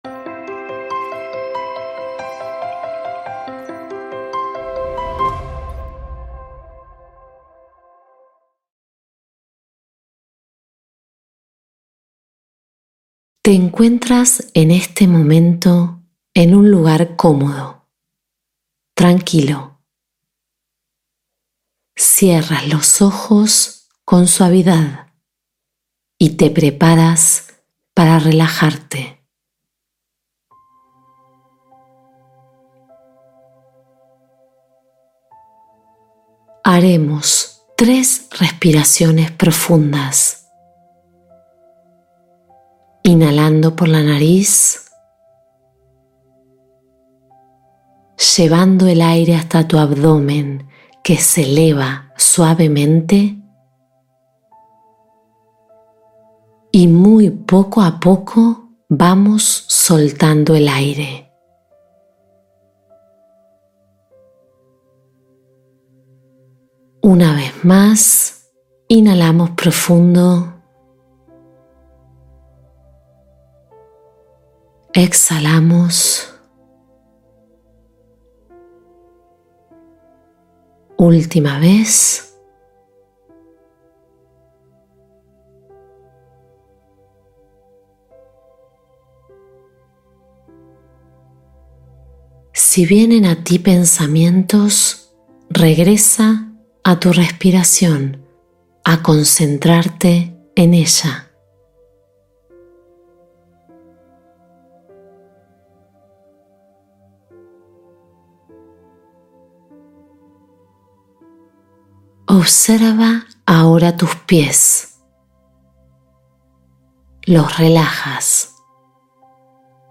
Cuando Todo Fluye Mejor: Meditación para Abrir Caminos Internos